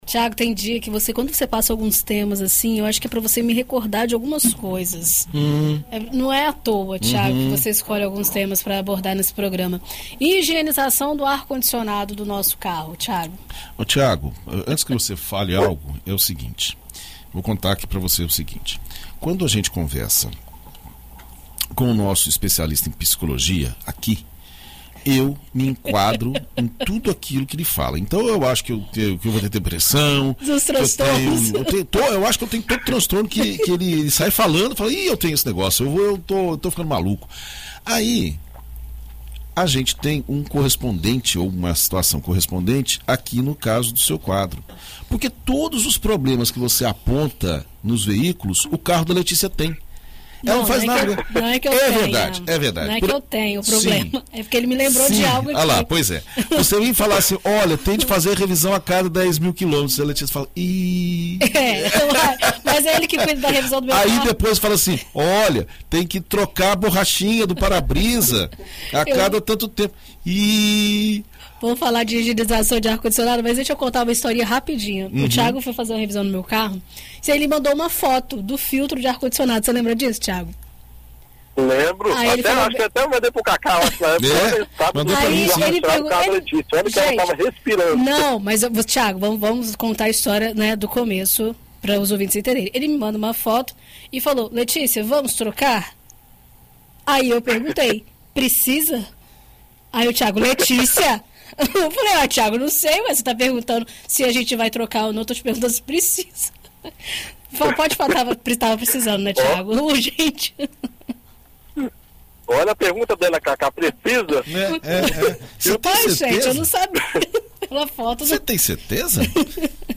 Na coluna Motorizado desta quinta-feira (15), na BandNews FM Espírito Santo